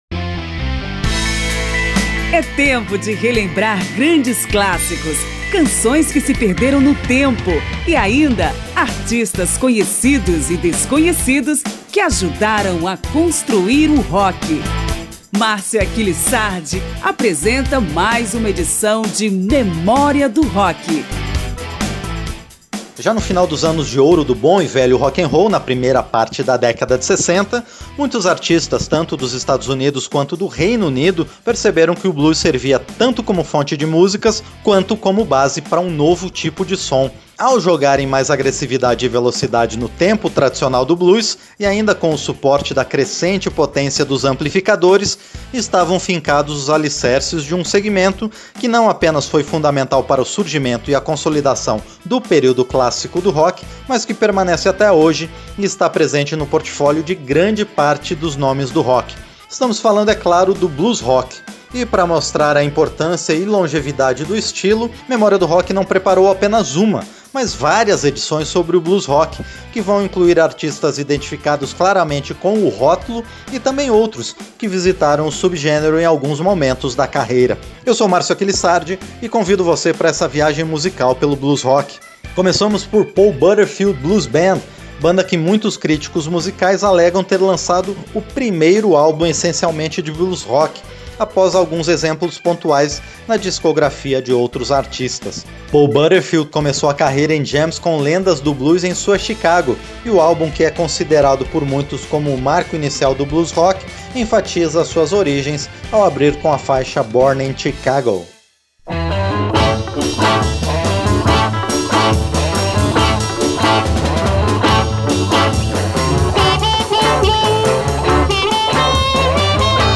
Blues rock - Parte 1 - Rádio Câmara